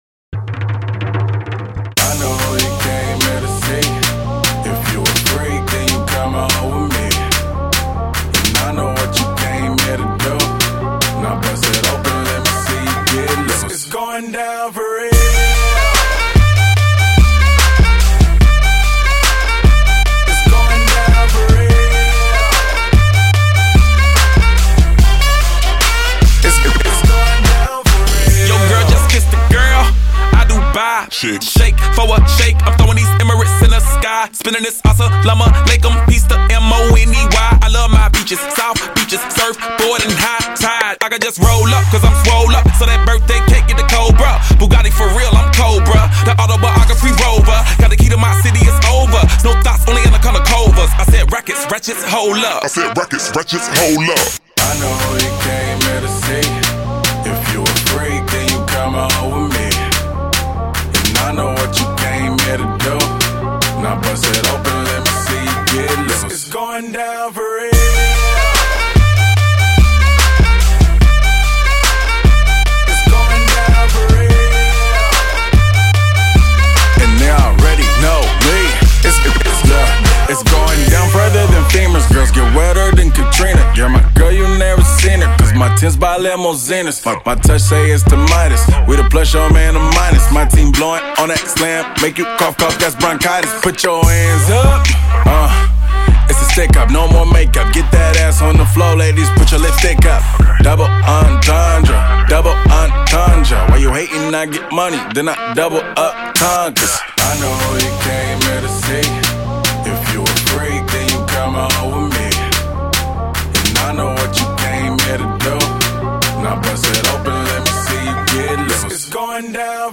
ما تونستیم براتون بهترین موزیک های بیس دار مخصوص